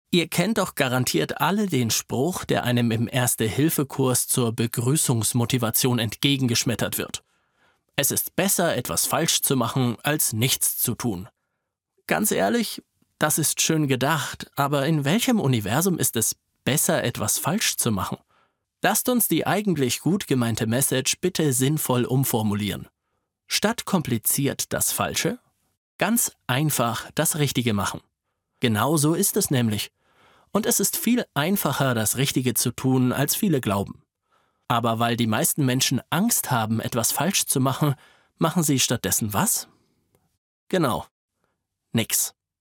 Hochwertige Sprachaufnahmen mit einem erfahrenen Hörbuchsprecher – in bester Studioqualität.
Scheisse-Ein-Notfall-Hoerprobe.mp3